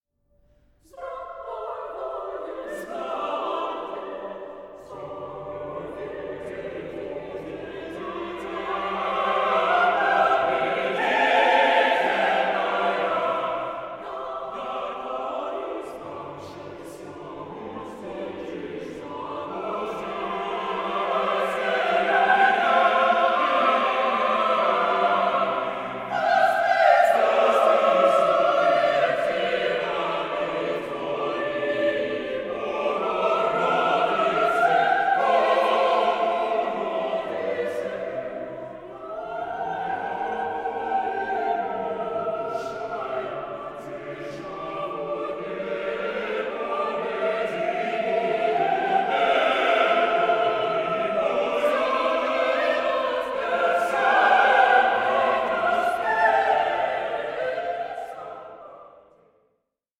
choral masterpiece